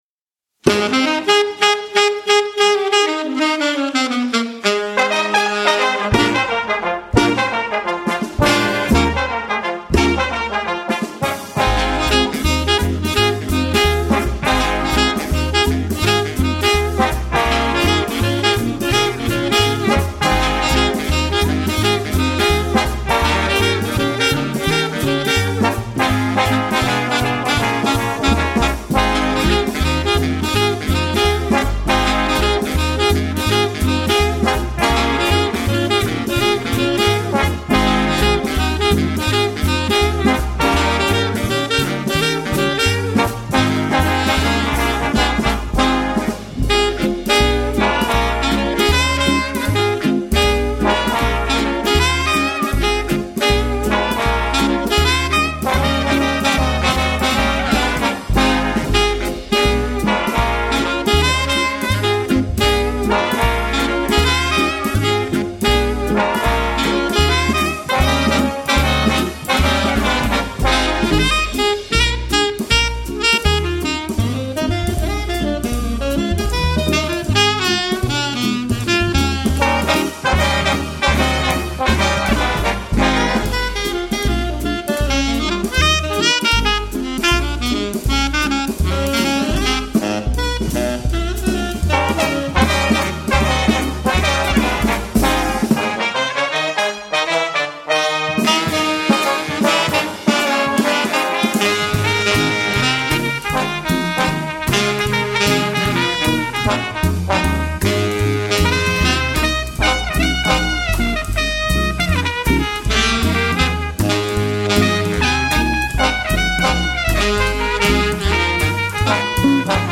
Jazz Duo / Jazz Trio and Big Band with Vocals